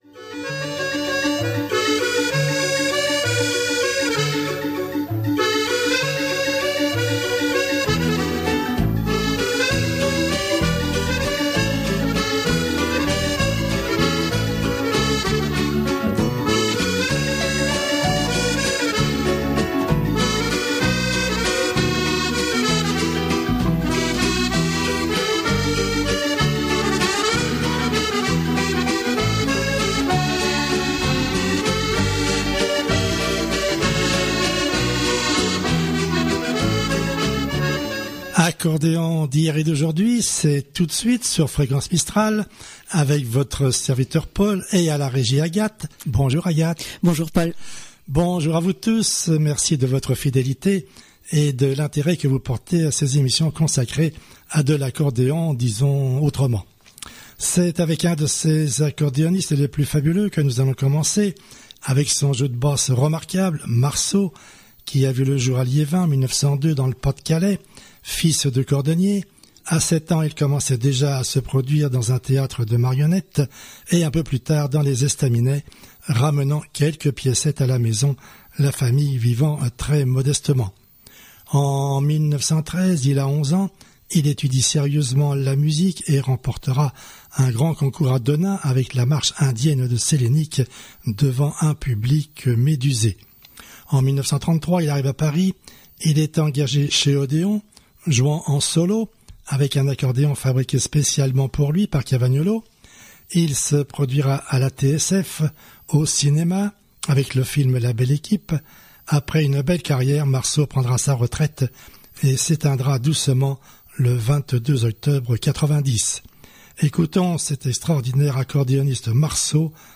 pour les amoureux d'Accordéon